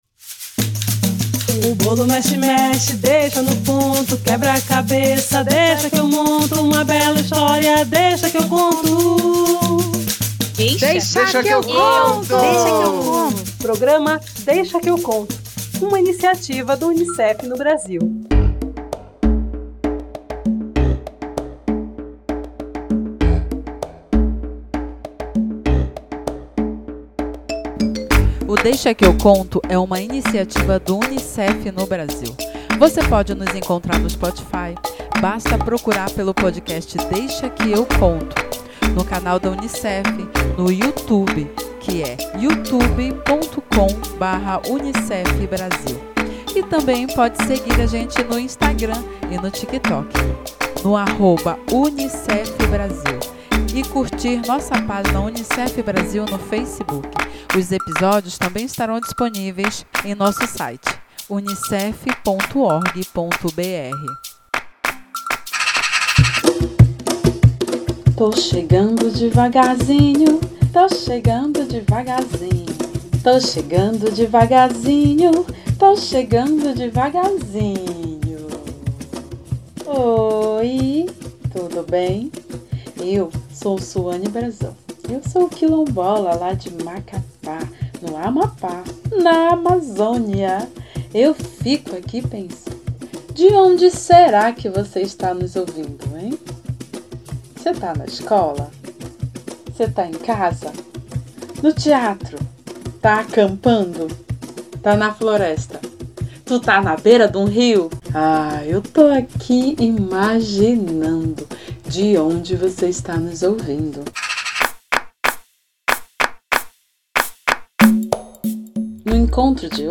Uma entrevista divertida